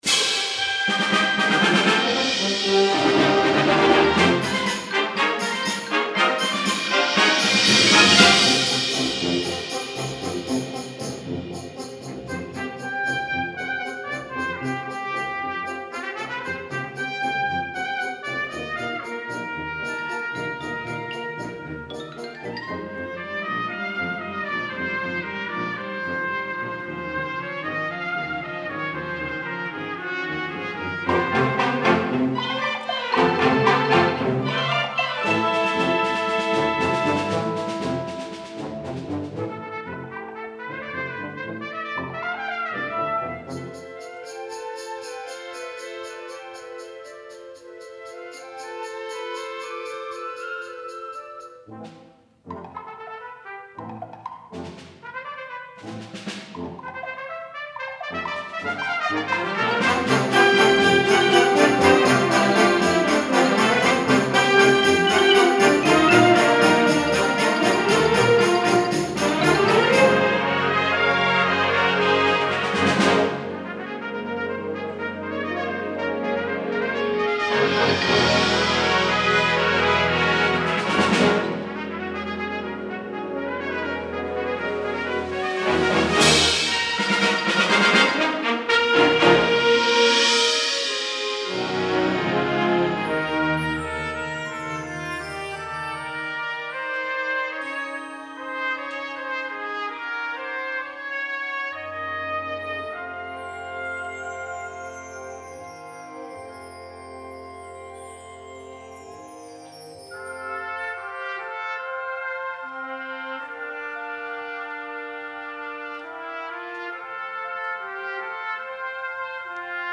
Voicing: Trumpet w/ Band